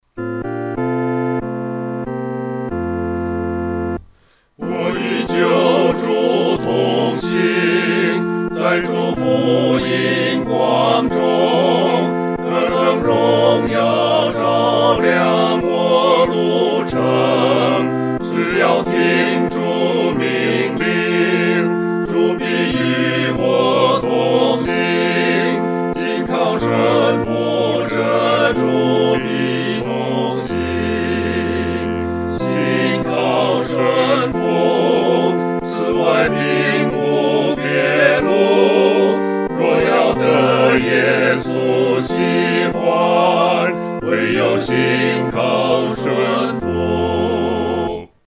合唱（四声部）
信靠顺服-合唱（四声部）.mp3